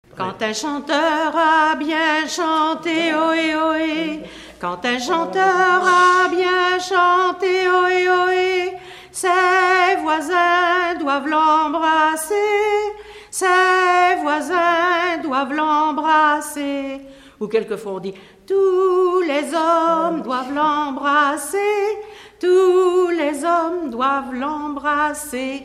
Pièce musicale inédite